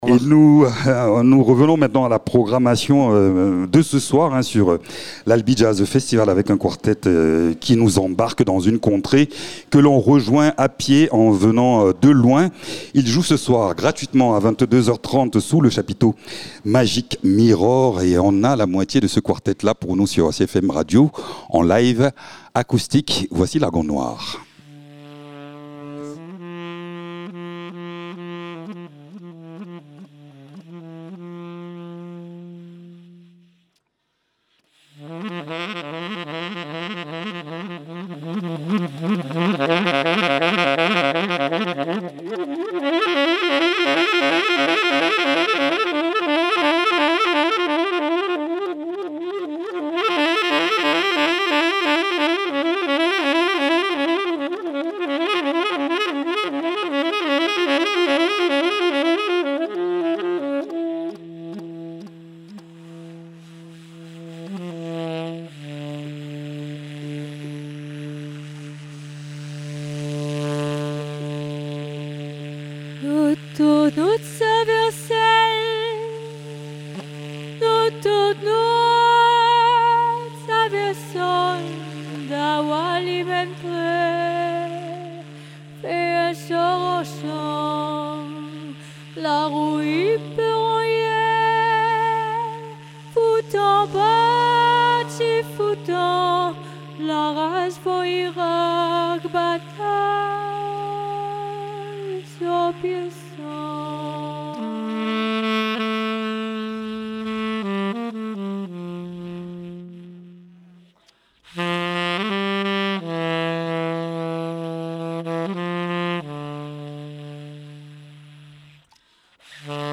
Quatre musiciens sensibles et singuliers pour un télescopage électrique de remous de synthétiseurs, basse fender, batterie de calebasses avec ou sans corde, saxophone arraché et textes débrayés… Lagon Nwar souligne avec poésie les contours d’un dialogue entre la tradition créole, l’afro-jazz et la pop européenne.
Albi Jazz Festival